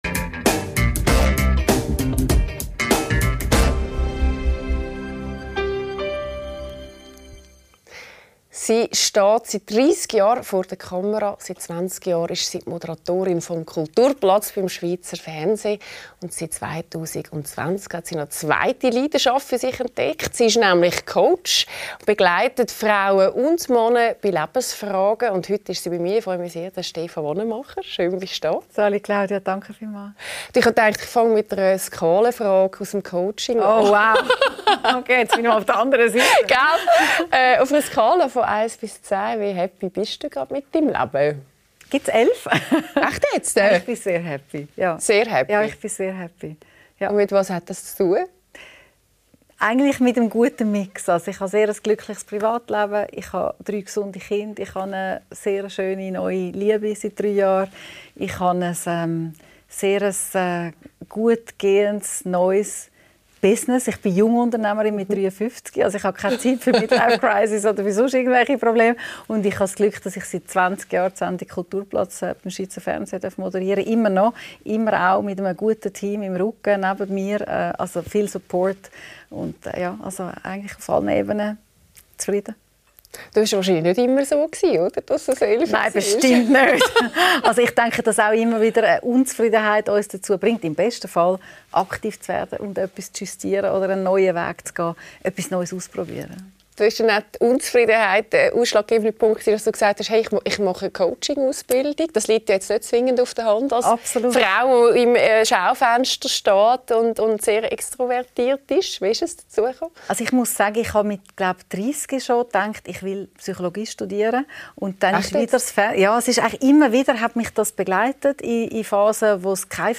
Die Talkshow